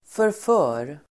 förför [förf'ö:r]